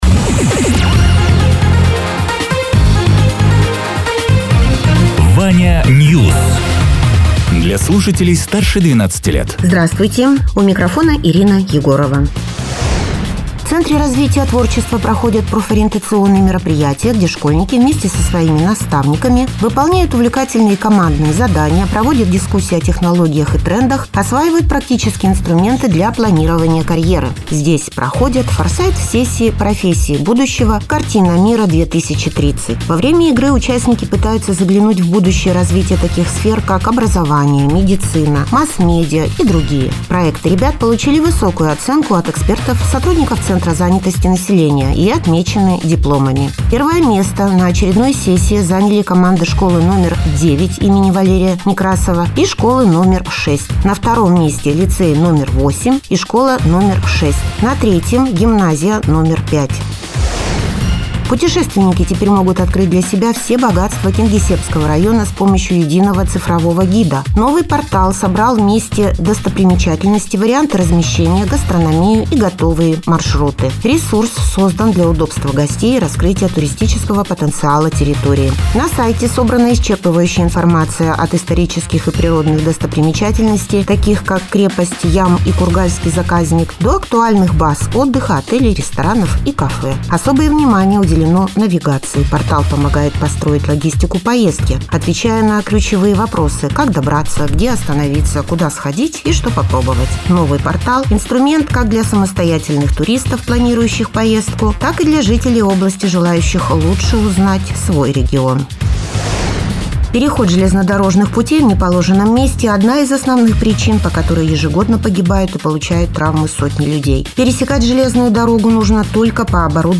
Радио ТЕРА 30.01.2026_10.00_Новости_Соснового_Бора